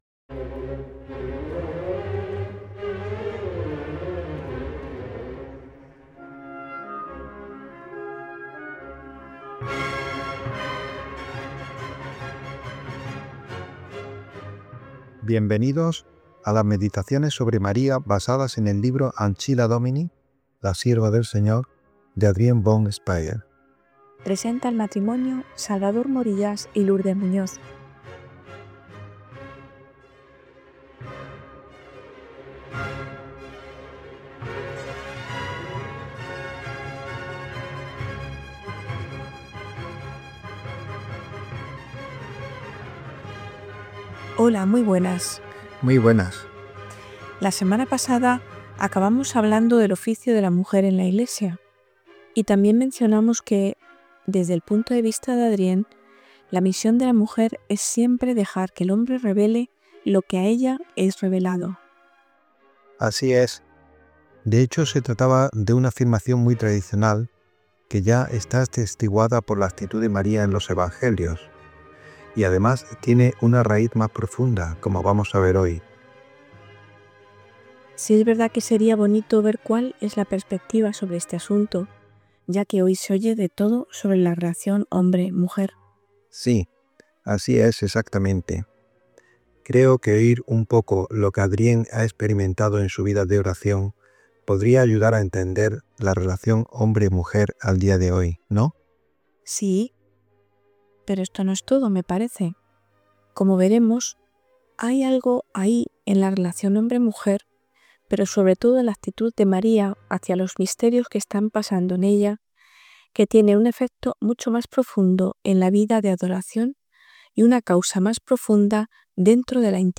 El sí de la Virgen María y su entrega sin límites nos presenta el modelo de la actitud cristiana. Este podcast profundiza en esta realidad a través de la lectura y la meditación del libro Ancilla Domini, de la mística suiza Adrienne von Speyr.
Las Contemplaciones marianas de Adrienne von Speyr han sido transmitidas semanalmente en el marco del programa «Vida consagrada» de Radio María España entre noviembre 2022 y octubre 2024.